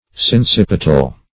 Search Result for " sincipital" : The Collaborative International Dictionary of English v.0.48: Sincipital \Sin*cip"i*tal\, a. (Anat.)